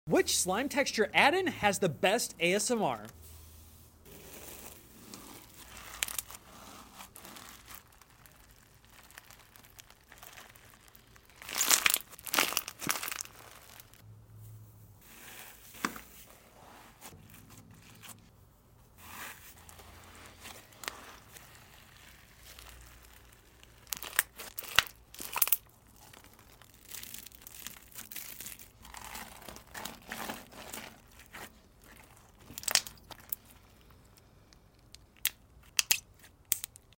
Which slime sounded the BEST? sound effects free download